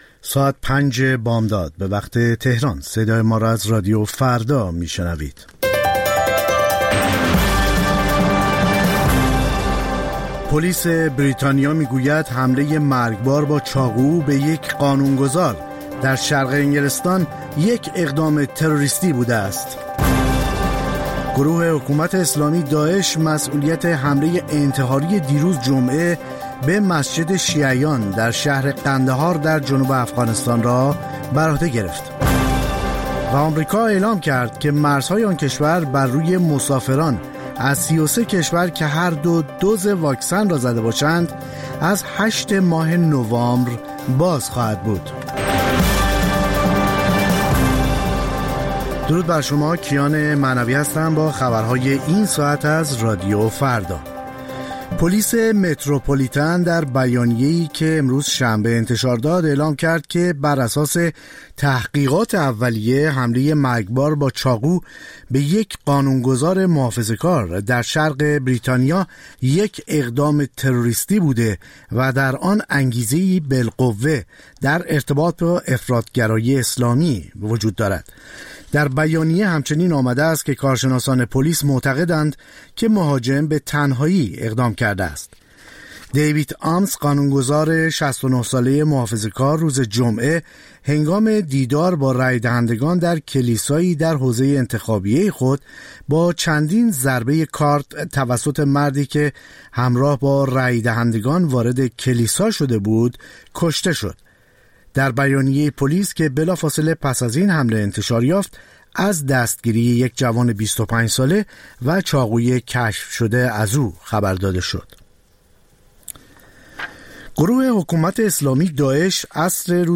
سرخط خبرها ۵:۰۰